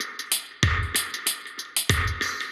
Index of /musicradar/dub-designer-samples/95bpm/Beats
DD_BeatC_95-01.wav